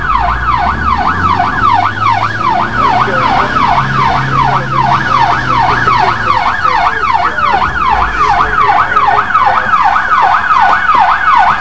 siren2.wav